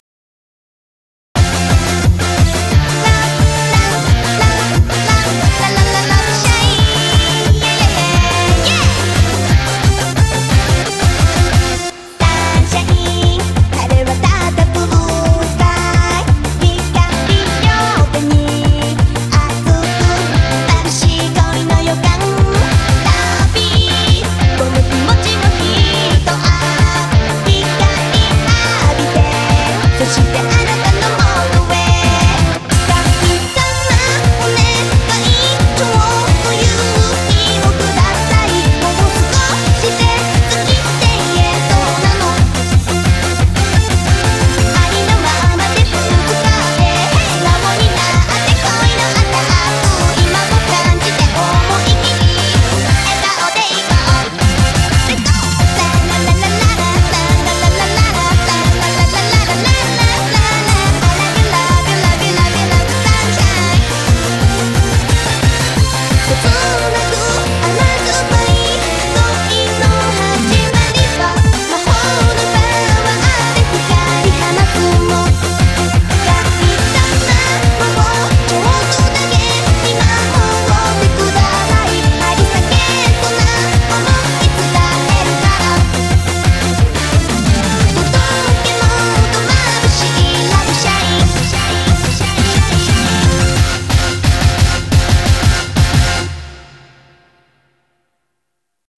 BPM177
Audio QualityMusic Cut